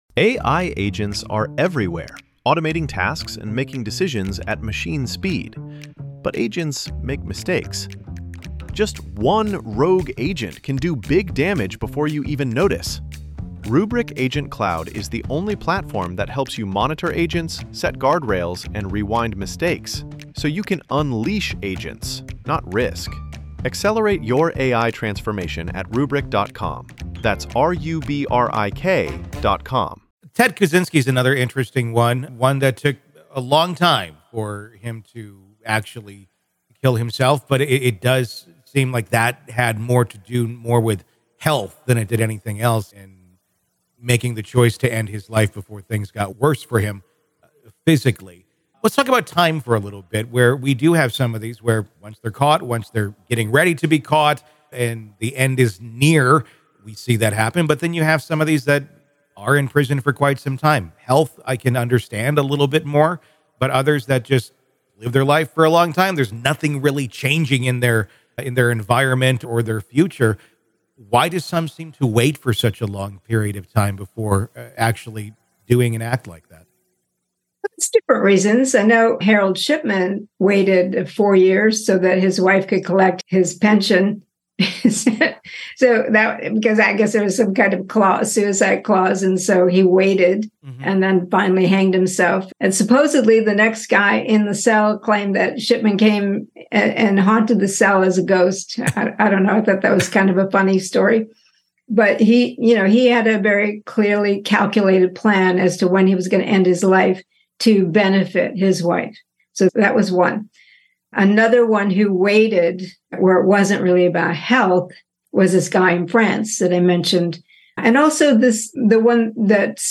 The conversation delves deep into the mindsets of notorious serial killers, examining their motivations for suicide and the factors influencing their timing.